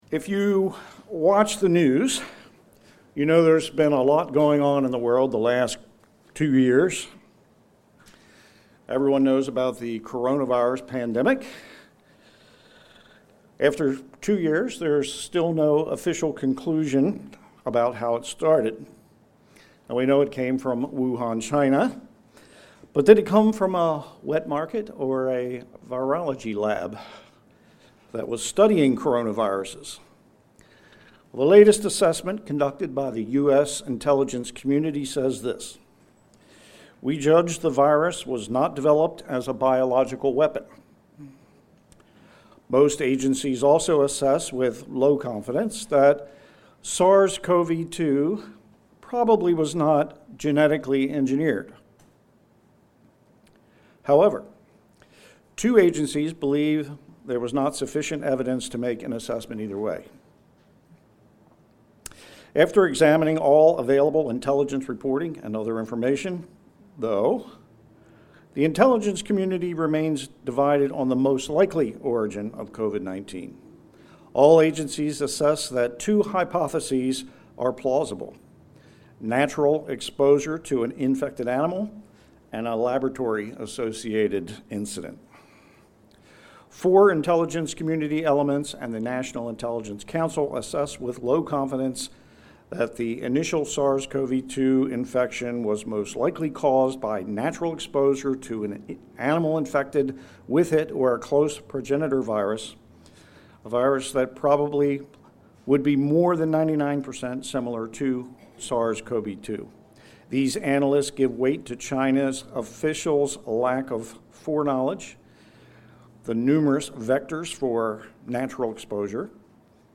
Given in Delmarva, DE